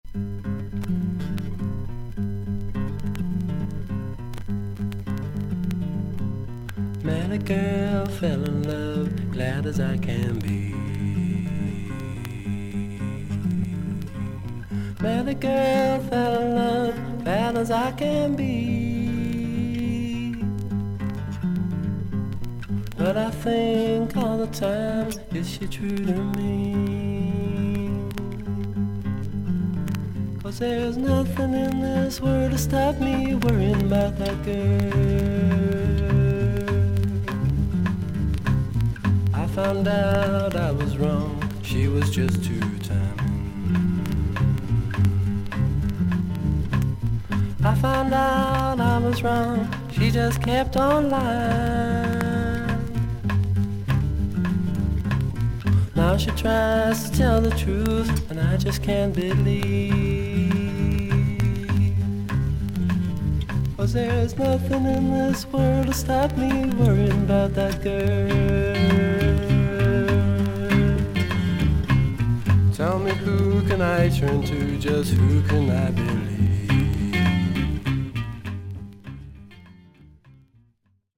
A3序盤に4mmのキズ、少々軽い周回ノイズあり。
少々サーフィス・ノイズあり。クリアな音です。